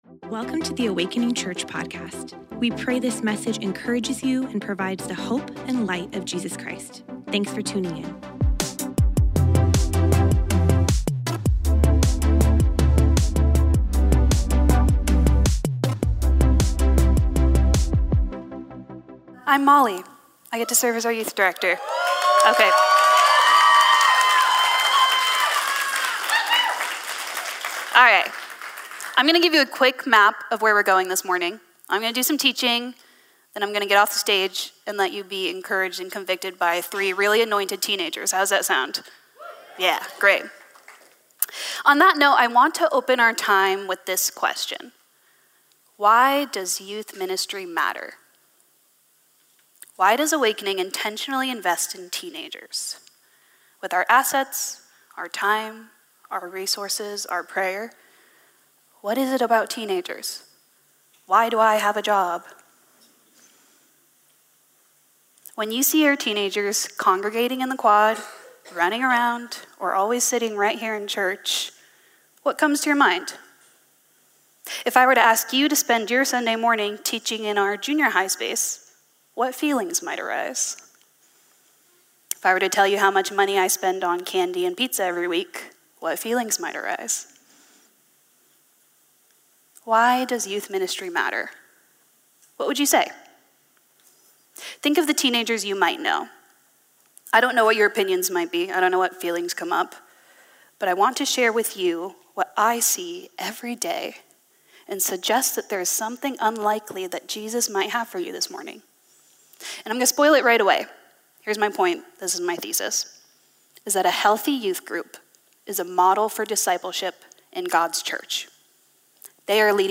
We’re hitting replay on one of the most jaw-dropping and inspiring conversations we’ve had on the podcast.